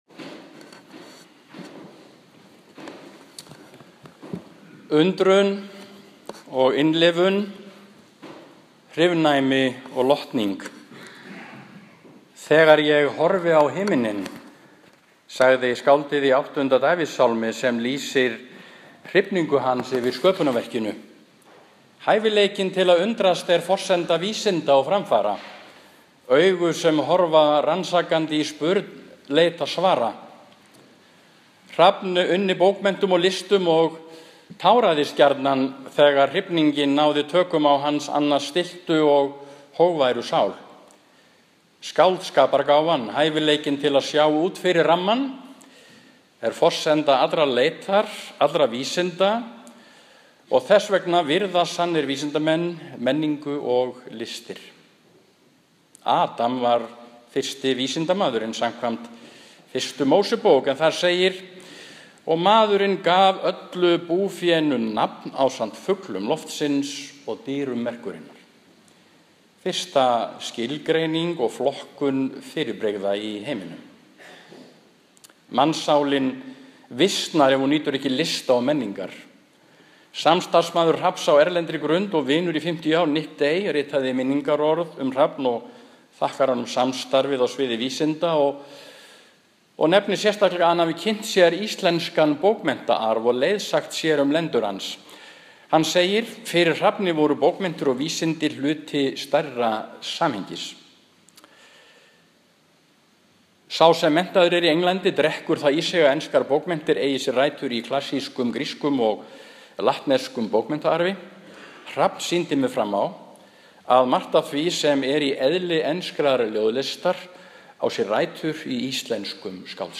Minningarorð